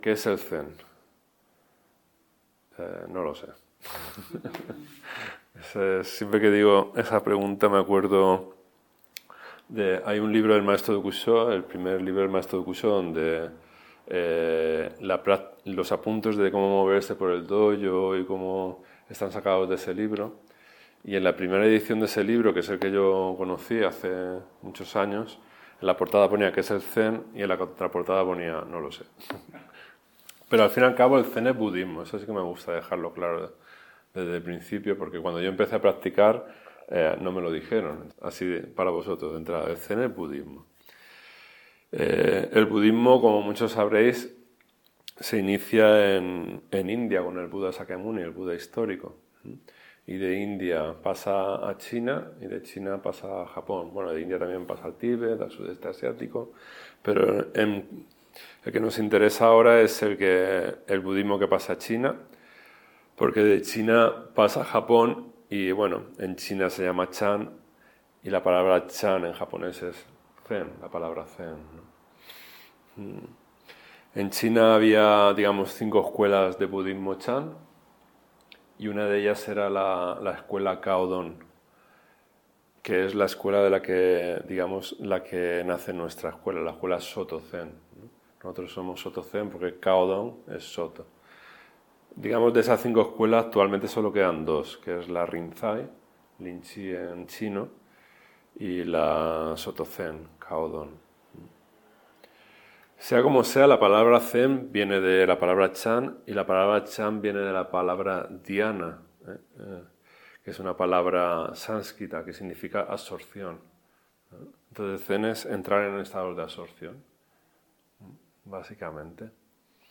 Grabación de la enseñanza impartida el 11 de octubre de 2024 en el Dojo Zen de Elche